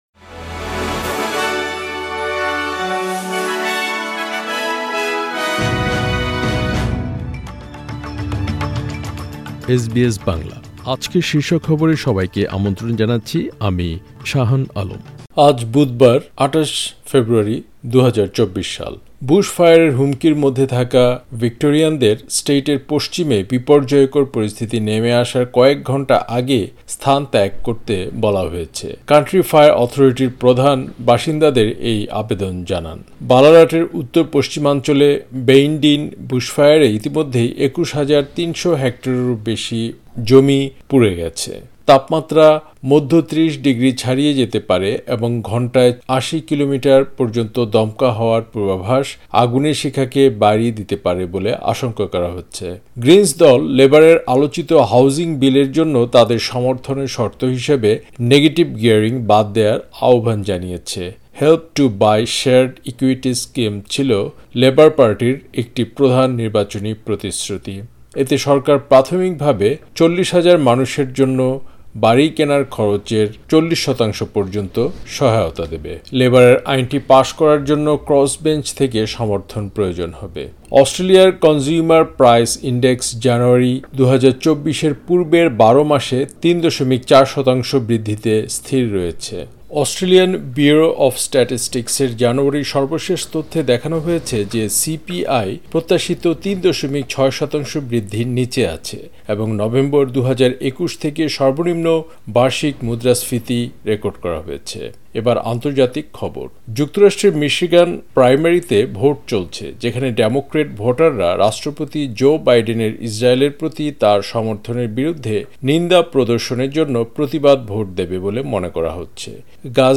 আজকের শীর্ষ খবর